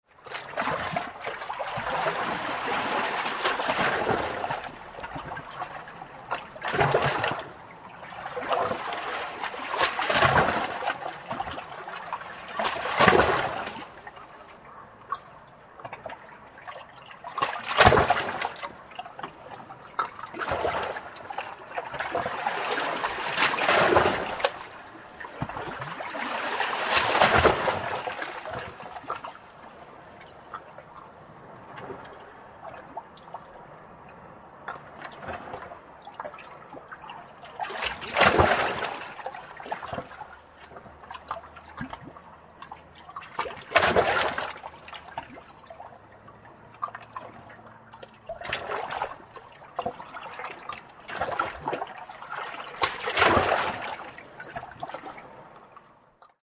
Small Sea Waves Lapping Against Rocks Sound Effect
Description: Small sea waves lapping against rocks sound effect. A close perspective of calm ocean waves sounds.
Genres: Sound Effects
Small-sea-waves-lapping-against-rocks-sound-effect.mp3